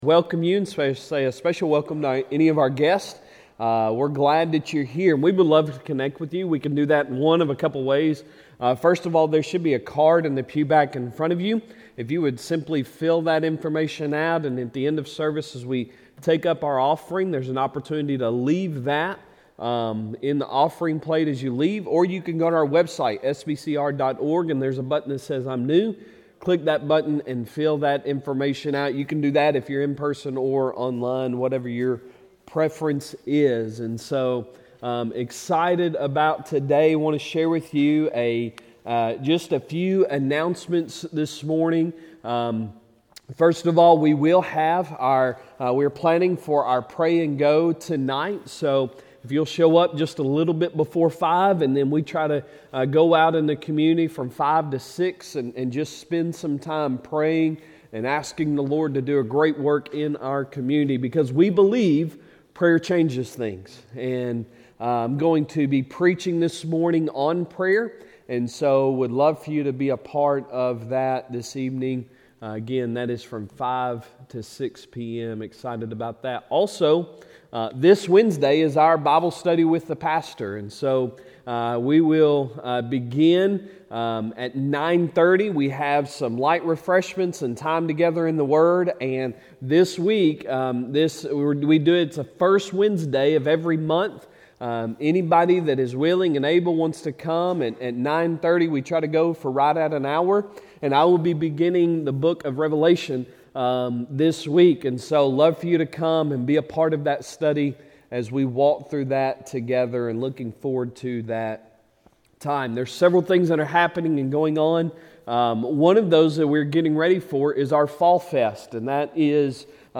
Sunday Sermon October 2, 2022